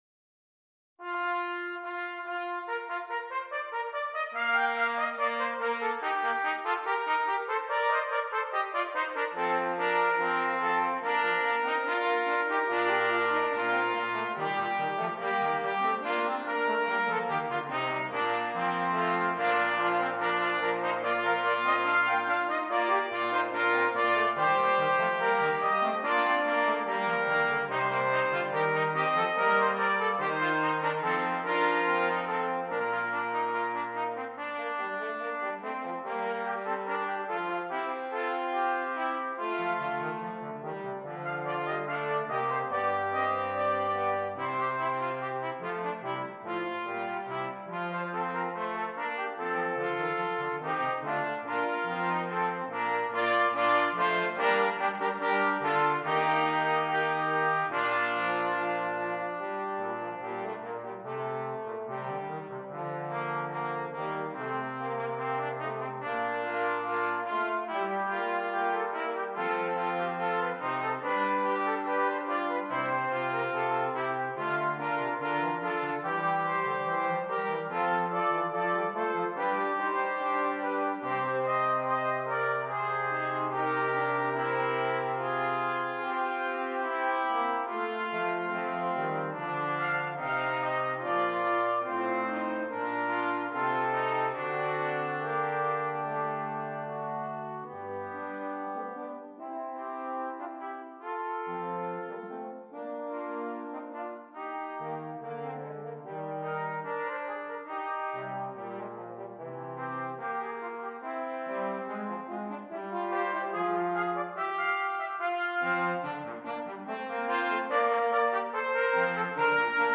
Brass Quartet
This famous canzon has been arranged for brass quartet.